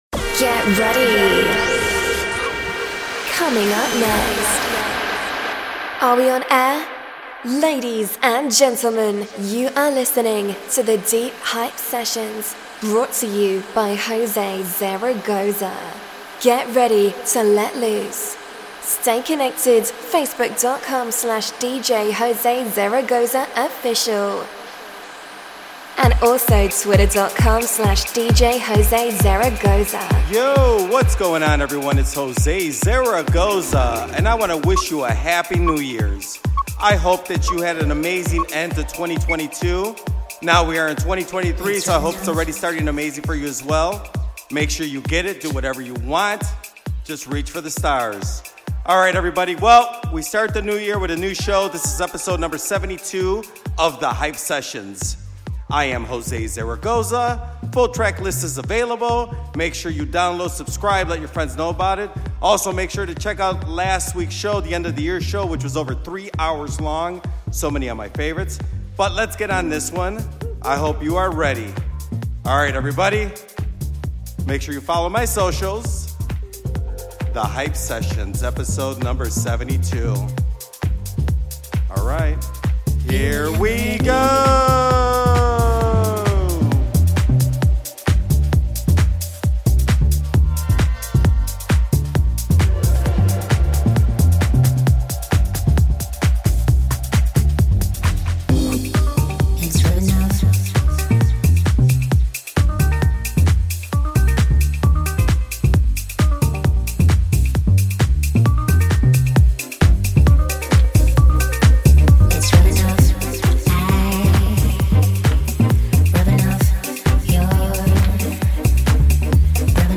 The first show of the year, and it's a funky deep one.